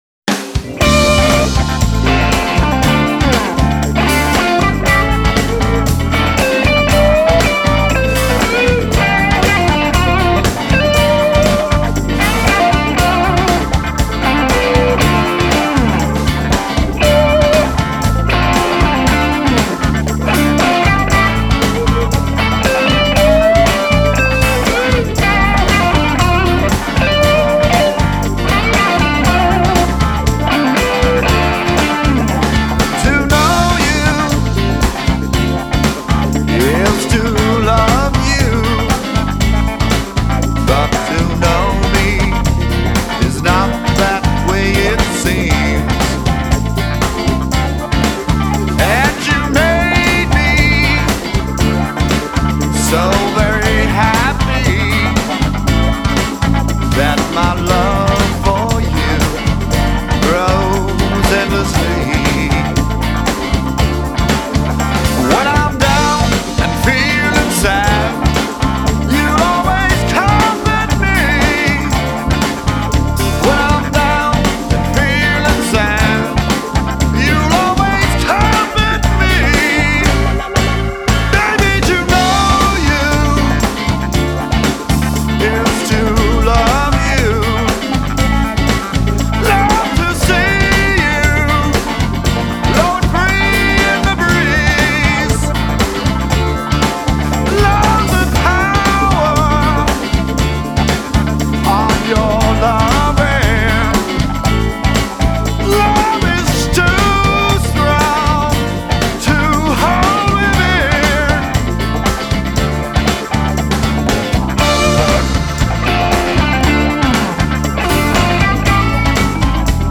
offers up a gutsy, yet classy sound